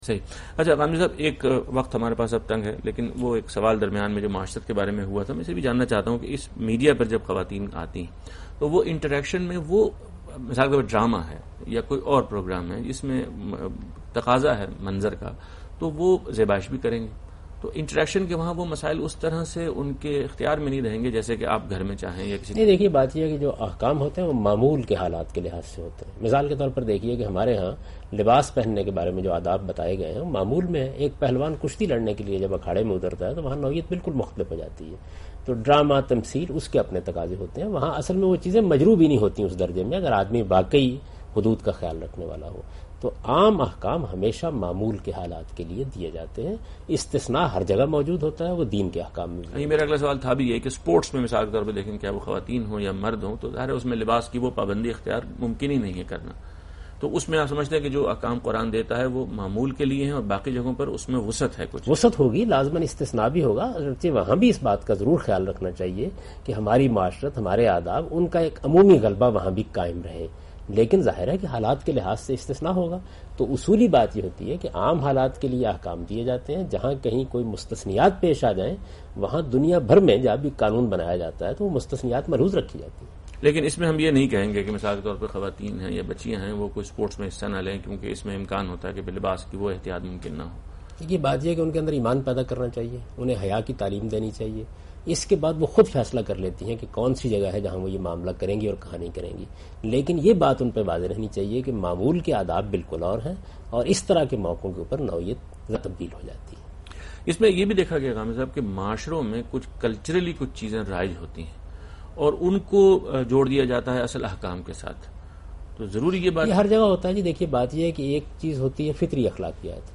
Category: TV Programs / Aaj Tv / Miscellaneous /
Question and Answers with Javed Ahmad Ghamidi in urdu, discussions_women, women